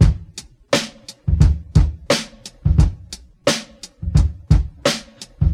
• 87 Bpm Drum Beat C# Key.wav
Free breakbeat - kick tuned to the C# note. Loudest frequency: 943Hz
87-bpm-drum-beat-c-sharp-key-v0Q.wav